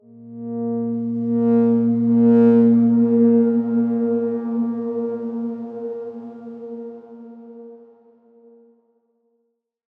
X_Darkswarm-A#2-mf.wav